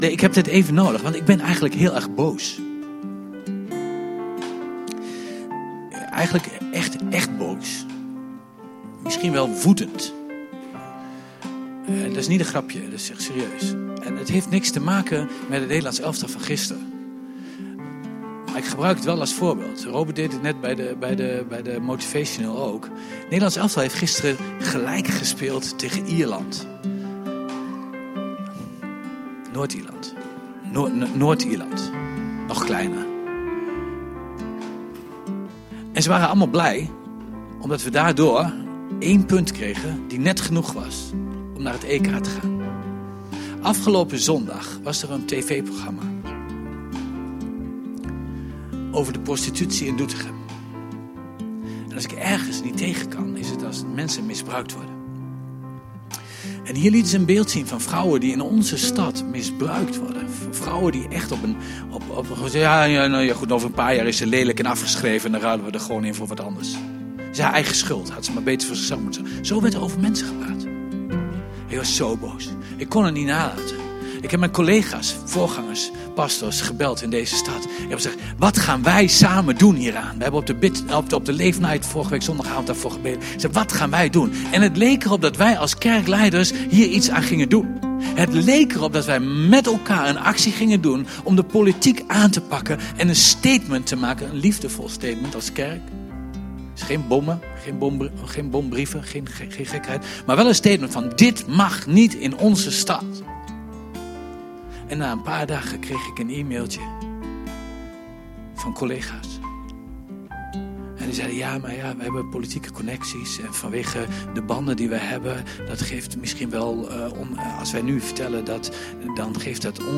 Zodat waar je zelf inzit of hebt meegemaakt je kan gebruiken om daar een ander mee te helpen. Luister de preek!